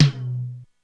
Index of /90_sSampleCDs/300 Drum Machines/Korg DSS-1/Drums01/03
MedTom.wav